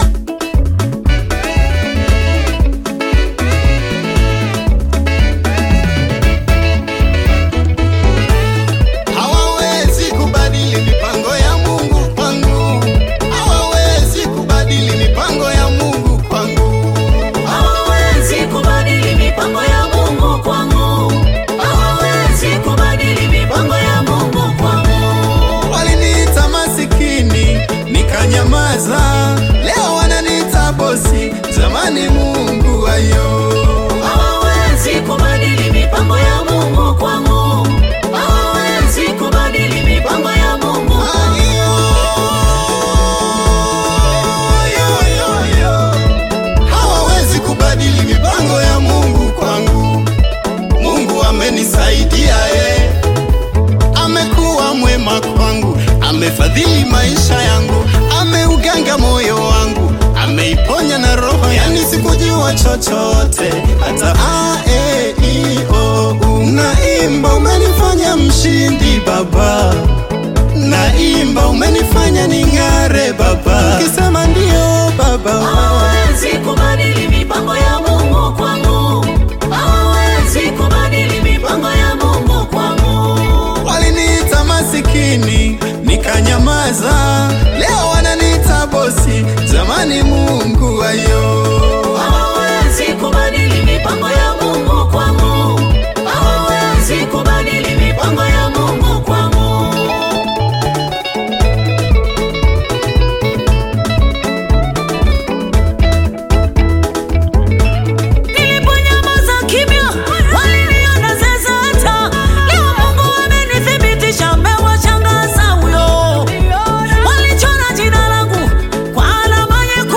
Gospel music track
Tanzanian gospel artists